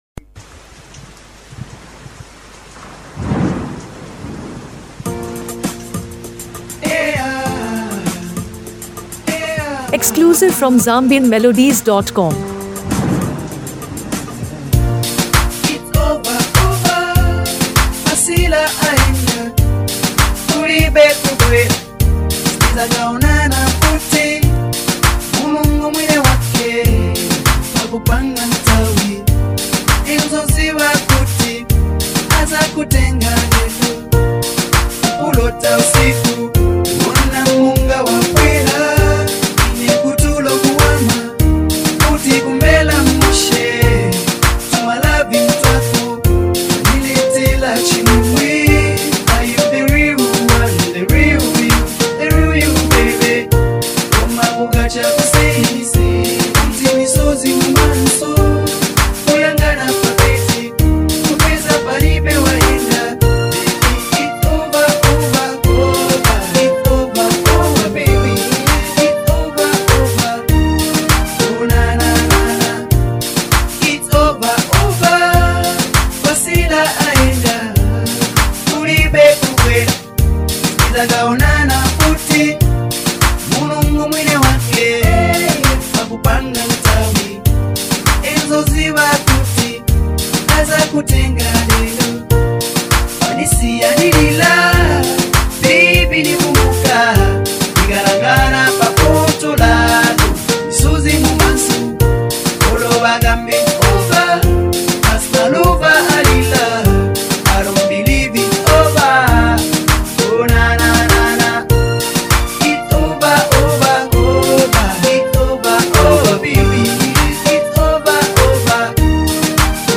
Zambian Music
praised for its authenticity and infectious rhythm.